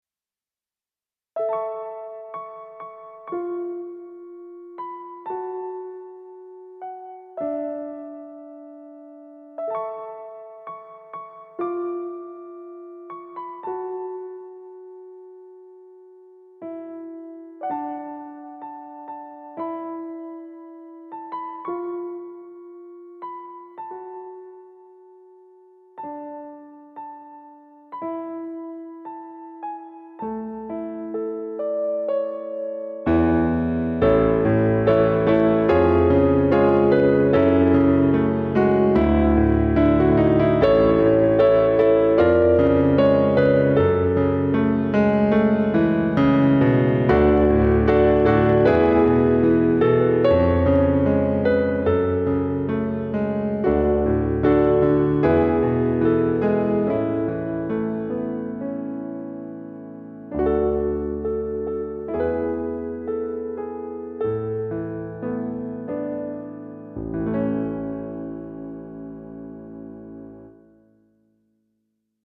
悲しみから生ま れる希望、単音から